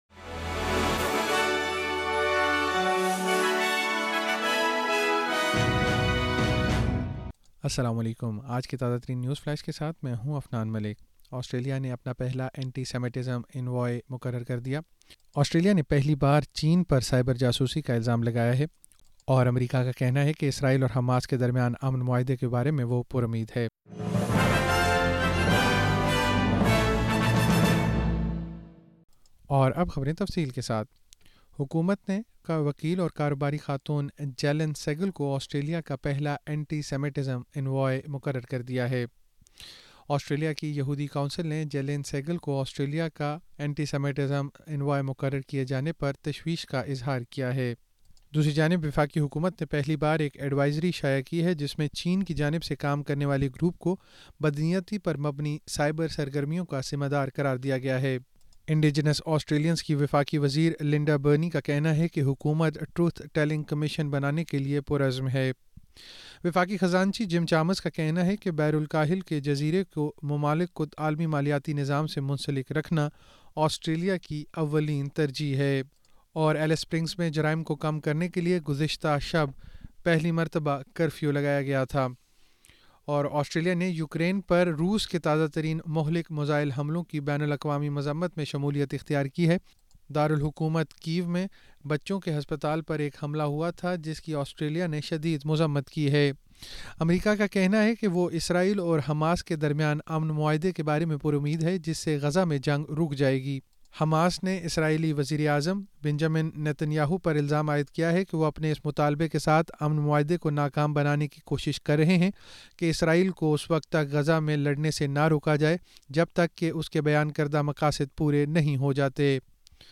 نیوز فلیش 09 جولائی 2024: آسٹریلیا نے اپنا پہلا اینٹی سیمیٹزم سفیر تعینات کر دیا